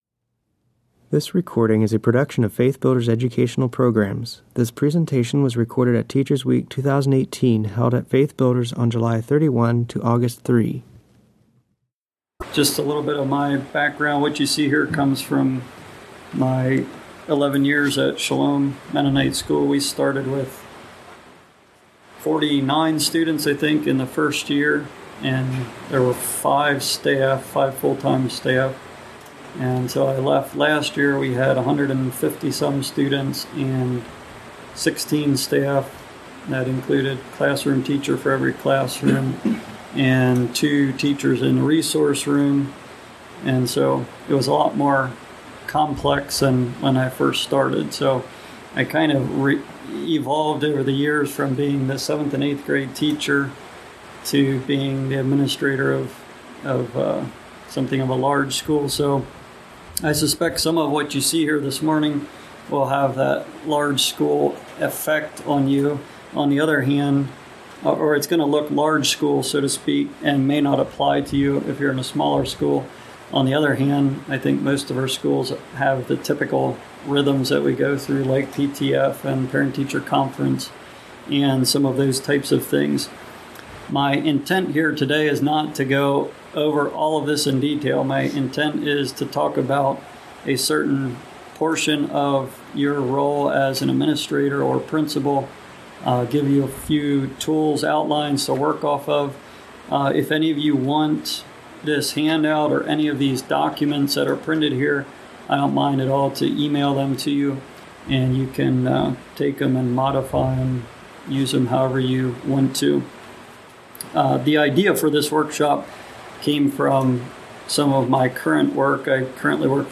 This presentation will help administrators identify the tools they need and provide samples for them to refine for their own toolbox. It discusses job descriptions, delegation, faculty handbooks, faculty evaluation, communicating with parents, and organizational assessments from the perspective of an administrator who has had as few as 5 staff and 50 students and as many as 15 staff and 150 students.